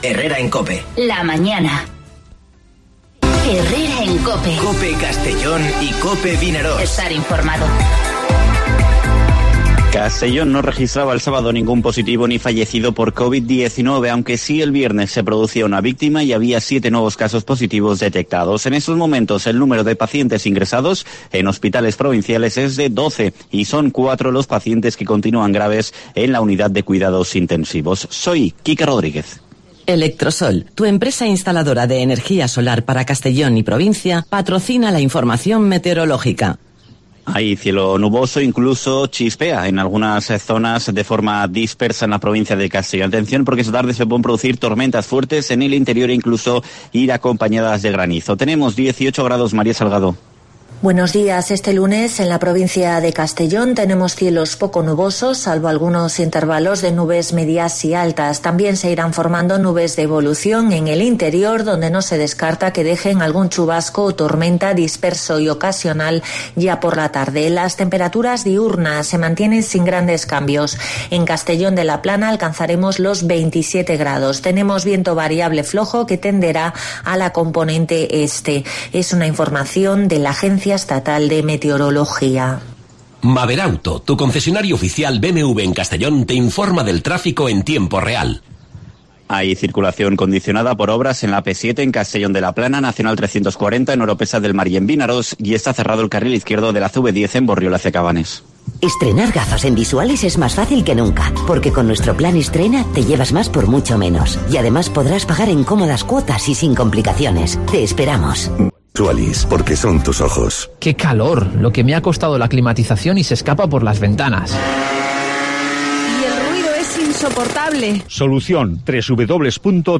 Informativo Herrera en COPE en la provincia de Castellón (01/06/2020)